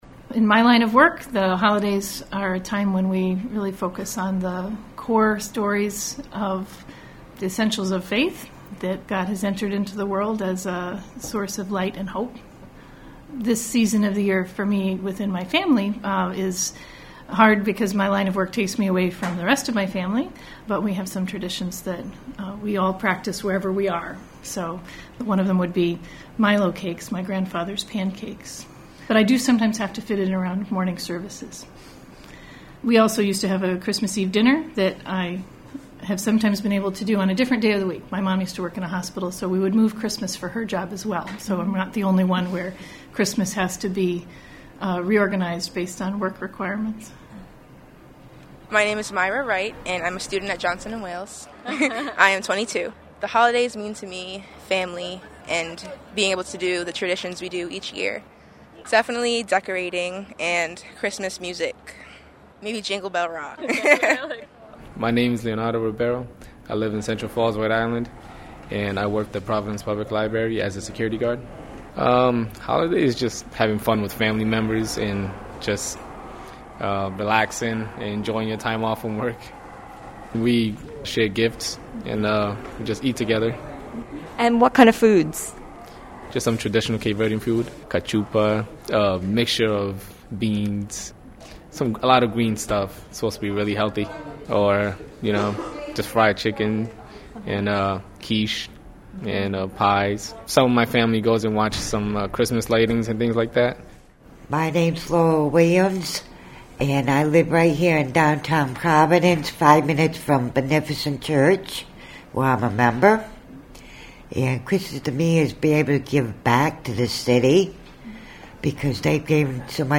Today we’ll hear about holiday rituals from five different Rhode Islanders.